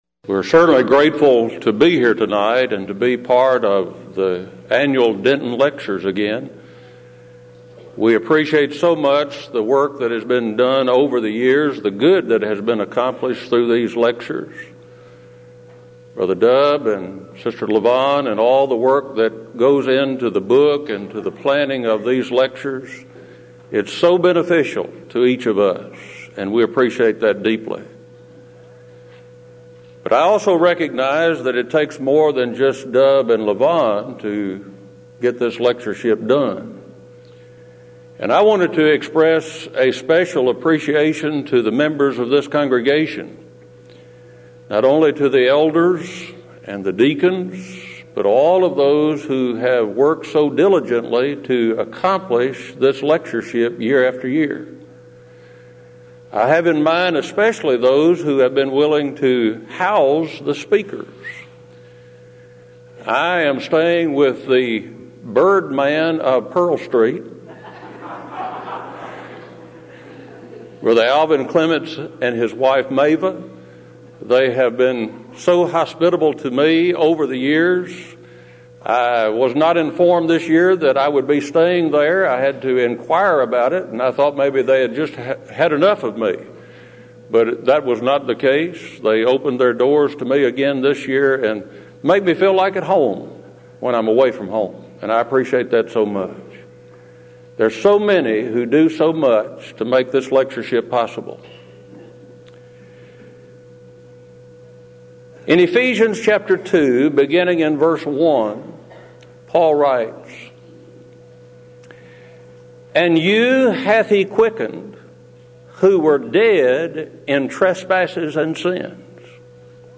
Event: 16th Annual Denton Lectures
If you would like to order audio or video copies of this lecture, please contact our office and reference asset: 1997Denton14